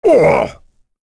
Phillop-Vox_Damage_01.wav